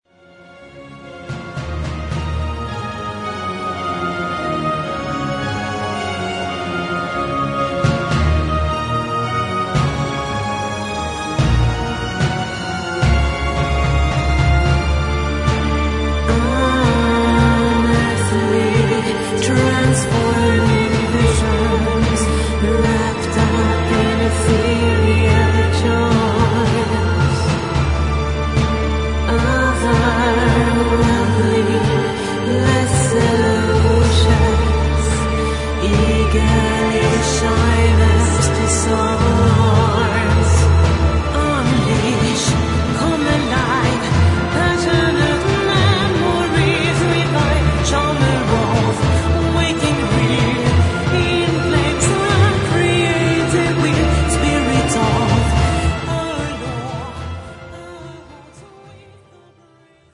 '11年度ゴシック界最高傑作、美声ソプラノ＋荘厳オーケストラルサウンド
vocals
特にソプラノボーカルが良く、透明感のある美しい声が一曲一曲をやさしく盛り上げてくれます。